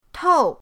tou4.mp3